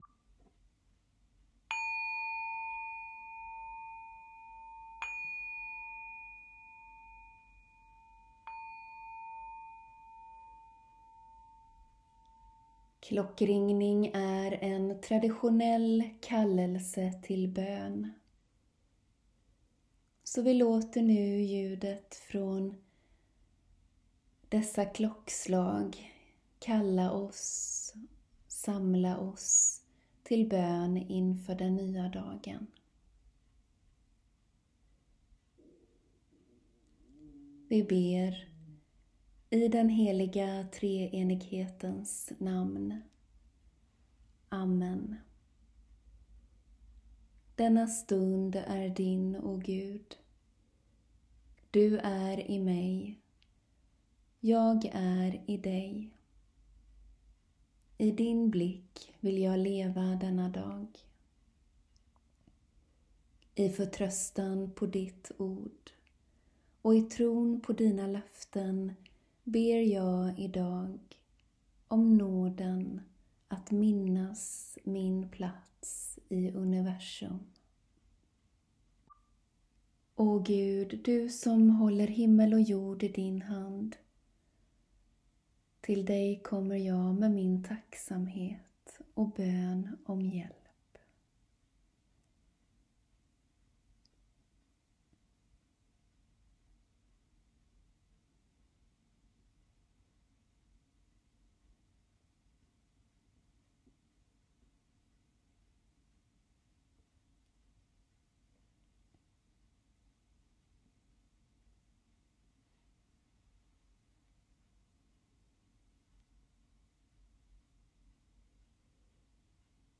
Bön inför dagen
Bön vid dagens början januari (6 min inkl. en längre tystnad för egen bön samt avslutande musik)